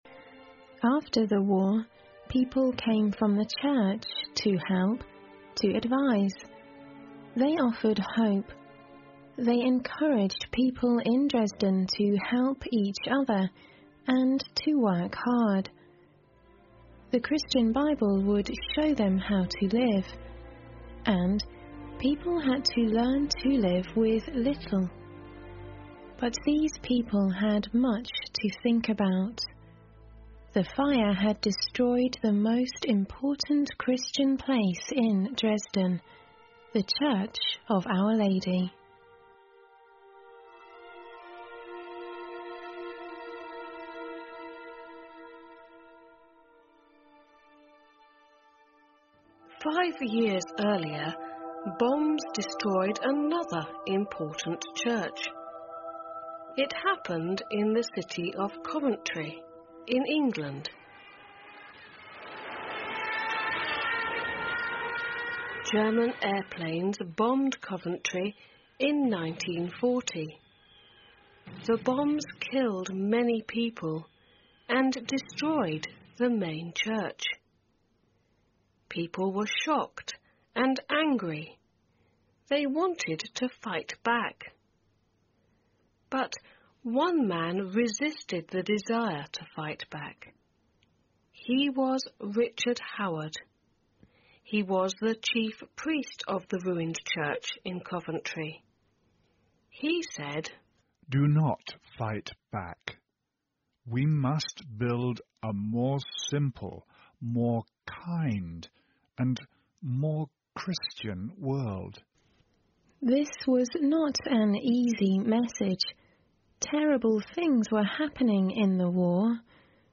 环球慢速英语 第7期:被毁的城市(2)